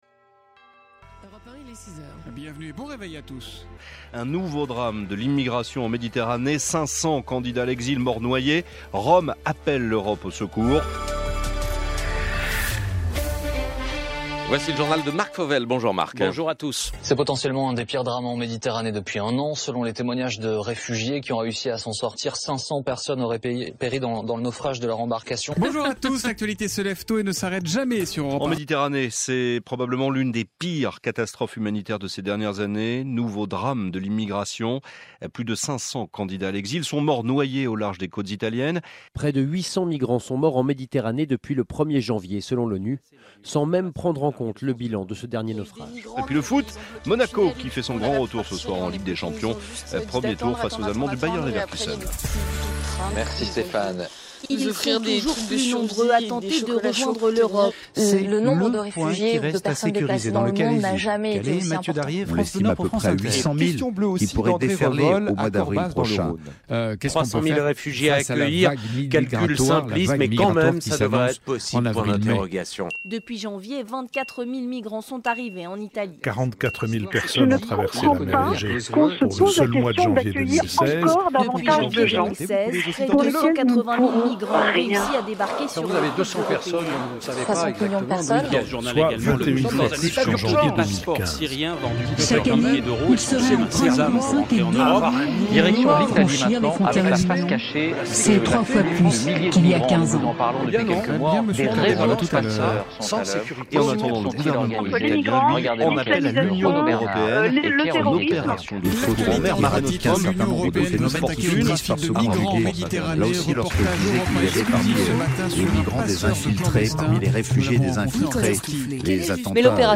Pièce sonore